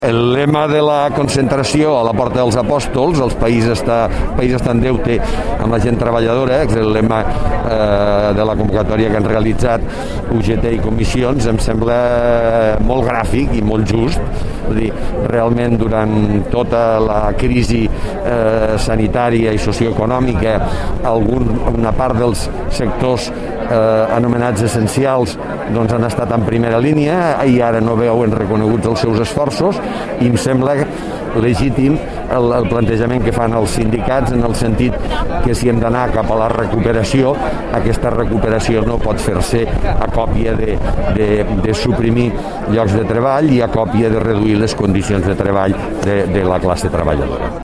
L’alcalde de Lleida legitima les reivindicacions dels sindicats durant la celebració de l’1 de Maig
tall-de-veu-miquel-pueyo